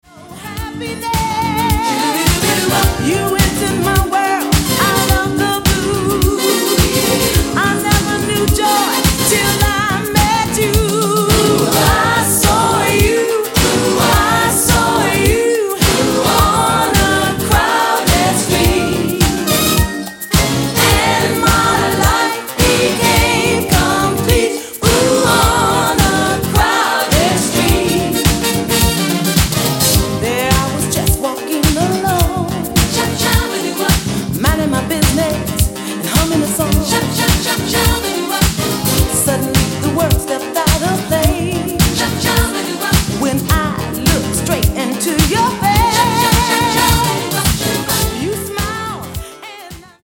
Funky, Afro, Soul Groove e JazzFunk Anni 70 e 80.
USA REMIX